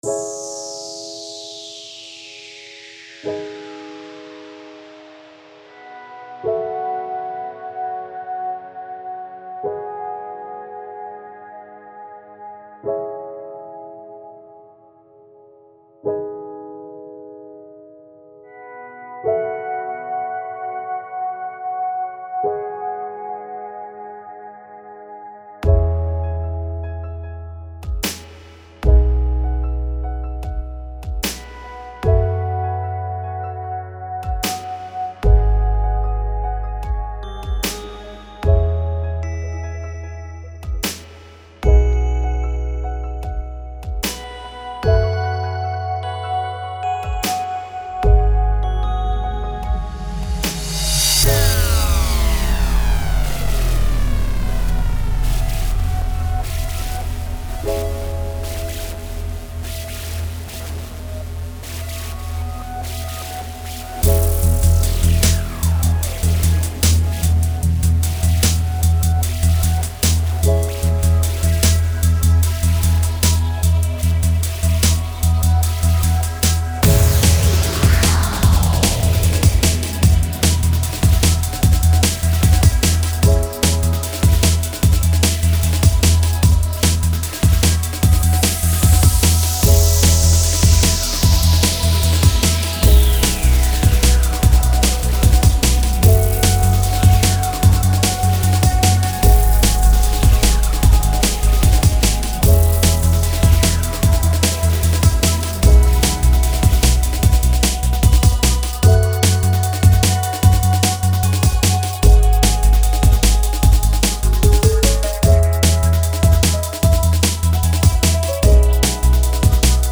Экспериментирую со стилем Chillout. Попробовал написать расслабляющую, но в то же время драйвовую мелодию.
Трек отмастерен для максимального комфортного и приятного прослушивания, качество наивысшее.